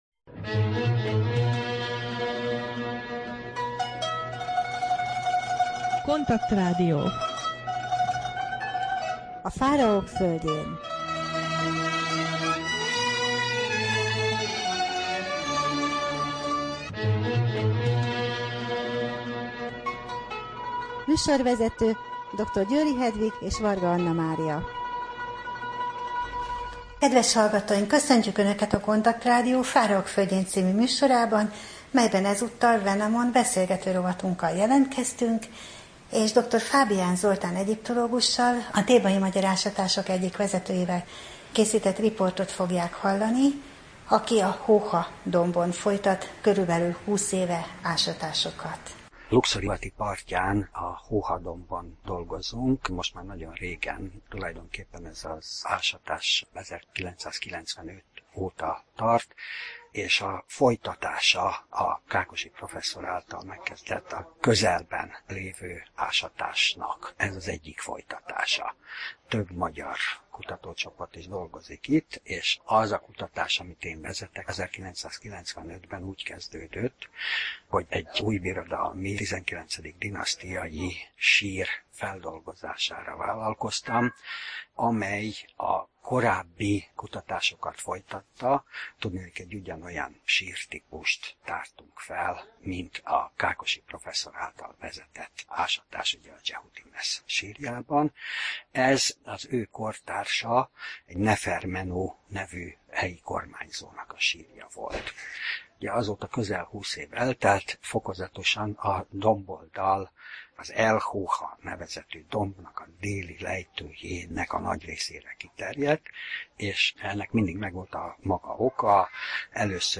Wenamon rovat: Riport
Rádió: Fáraók földjén Adás dátuma: 2014, Szeptember 19 Wenamon beszélgető rovat / KONTAKT Rádió (87,6 MHz) 2014. szeptember 19.